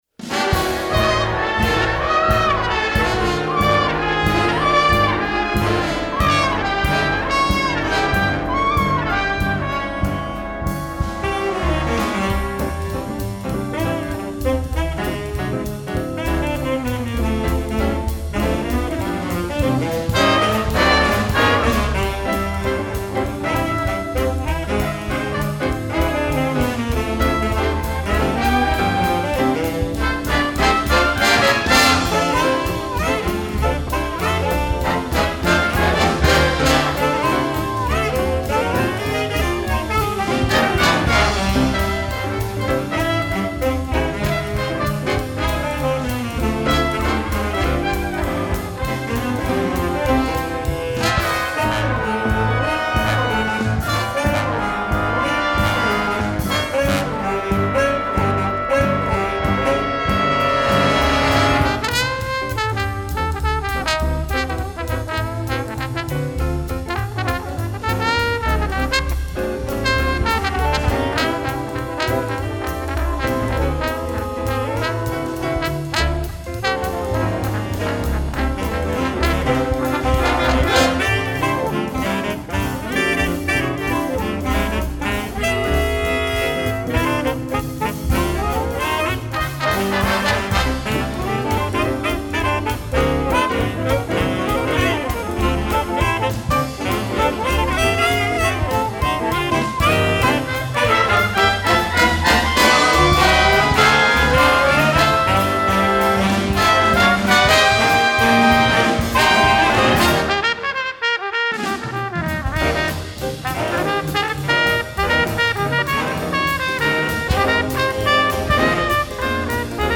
Studio Recordings: